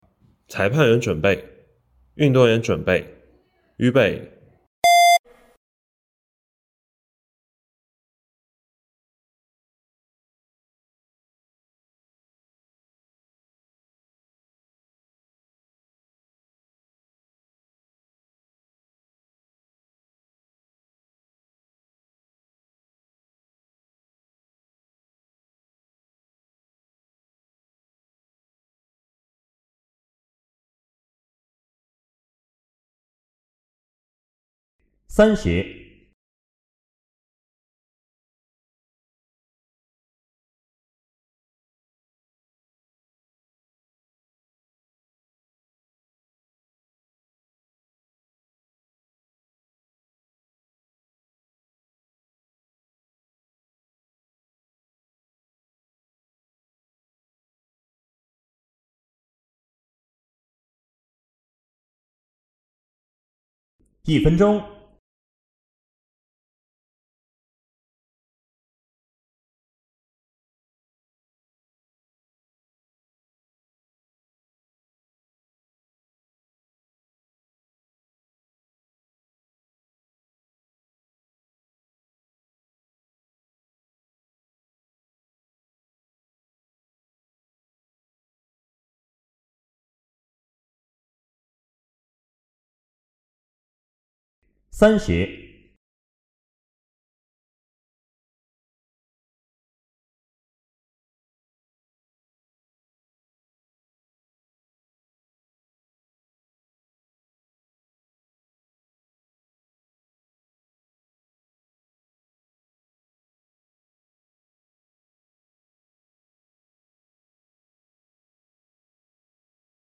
附件3：2022年合肥工业大学第一届跳绳大赛指定口令.mp3